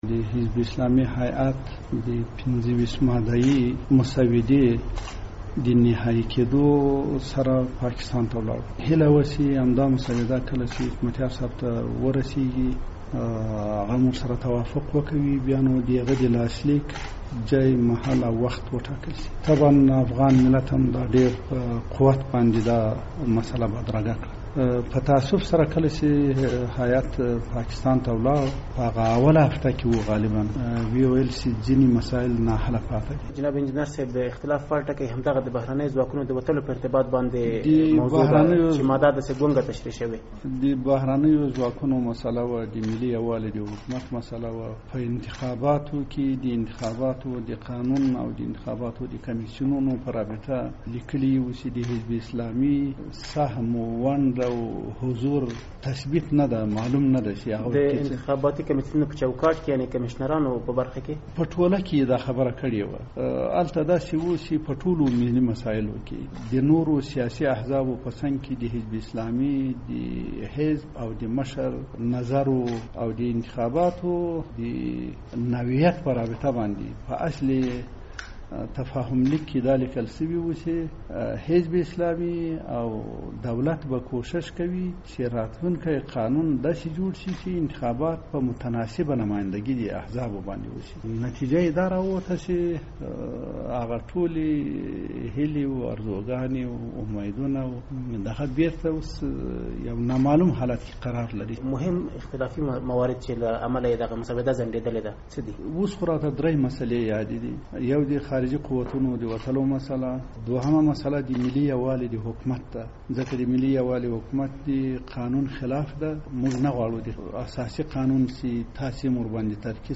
له انجنیر محمدخان سره مرکه